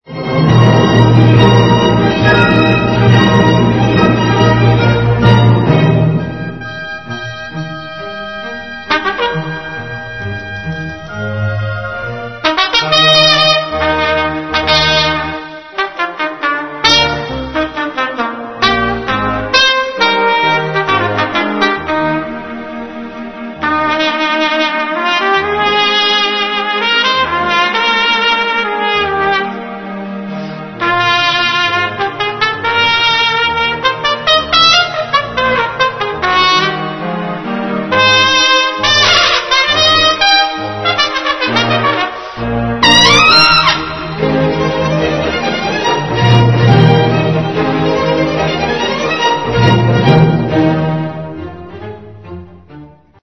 A new one of the opening of the Hummel Concerto. Supposedly showed up as a pre-audition tape for the Boston Symphony.